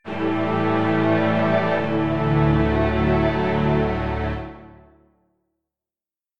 Success Resolution Video Game Sound Effect Strings
calm game happy positive resolution strings success triumph sound effect free sound royalty free Sound Effects